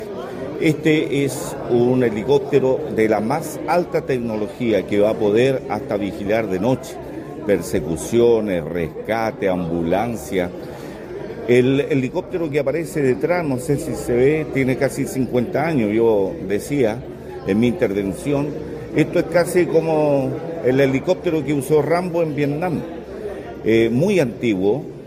Por su parte, el consejero regional, Miguel Ángel Contreras, señaló que este servicio es para la gente.